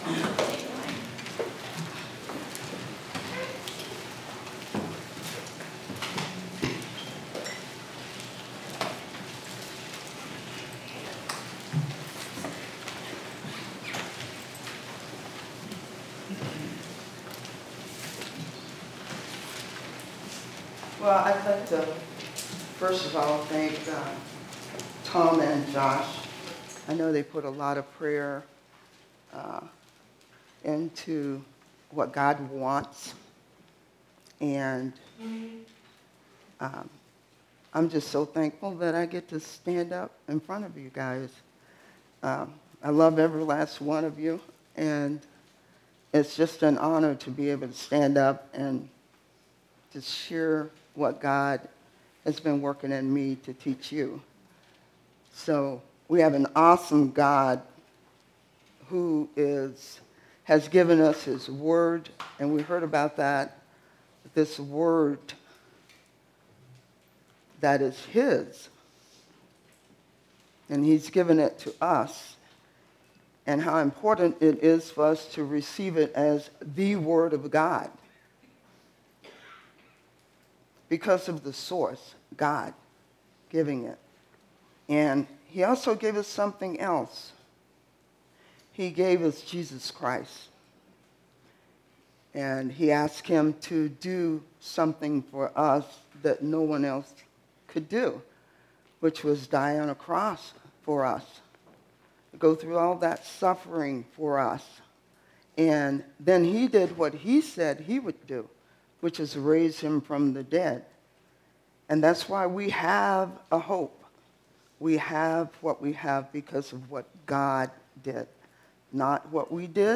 Part 8 in a verse-by-verse teaching series on 1 and 2 Thessalonians with an emphasis on how our hope helps us to live holy lives until Christ returns.
1 Thessalonians 5:1-11 Our Daily Hope (Family Camp 2024) – Part 8 July 31, 2024 Part 8 in a verse-by-verse teaching series on 1 and 2 Thessalonians with an emphasis on how our hope helps us to live holy lives until Christ returns.